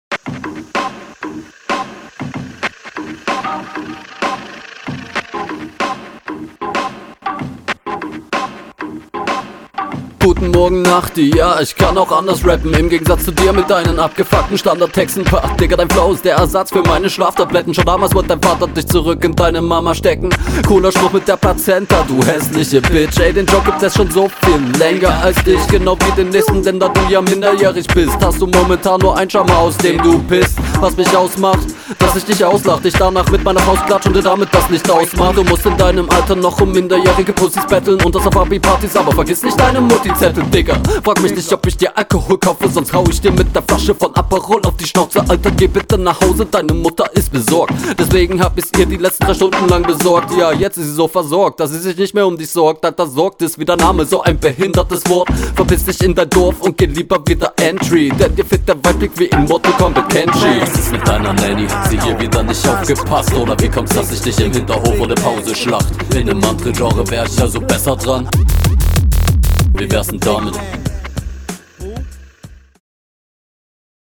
Battle Runden
Stimme mal cool eingesetzt.